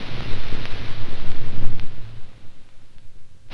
41 NOISE01-R.wav